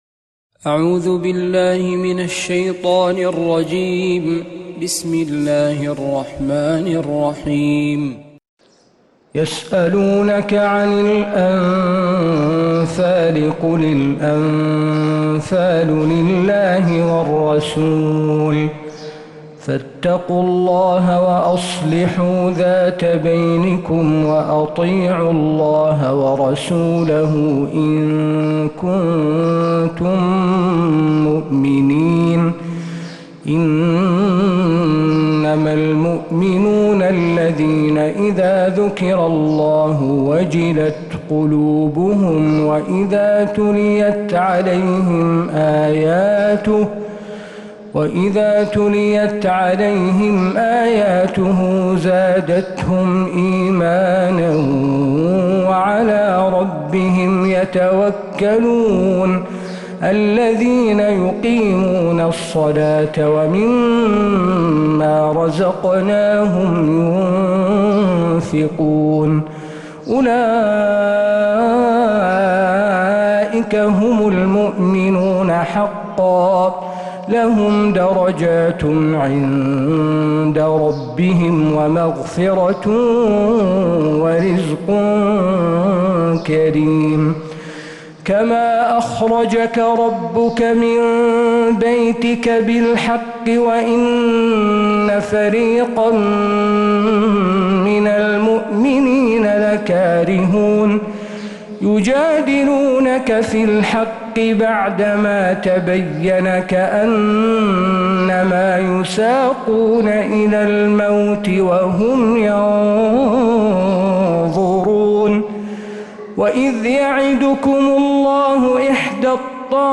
سورة الأنفال كاملة من فجريات الحرم النبوي